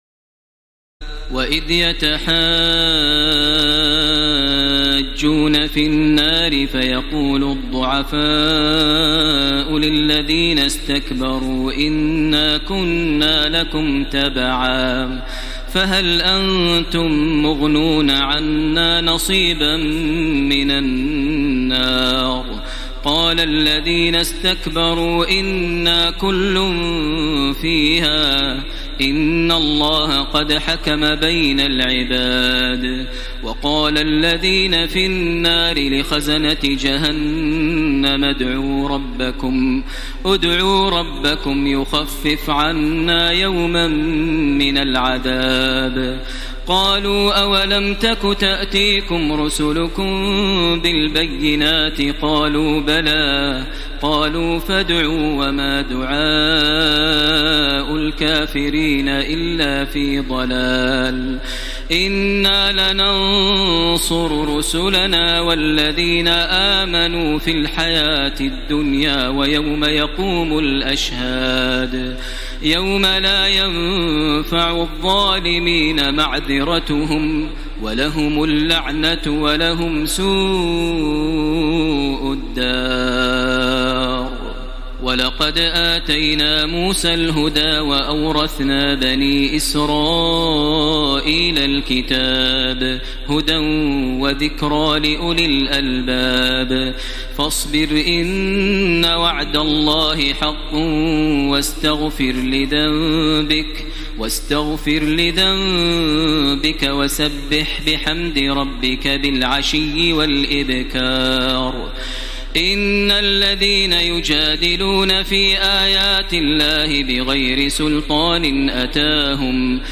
تراويح ليلة 23 رمضان 1434هـ من سور غافر (47-85) وفصلت (1-46) Taraweeh 23 st night Ramadan 1434H from Surah Ghaafir and Fussilat > تراويح الحرم المكي عام 1434 🕋 > التراويح - تلاوات الحرمين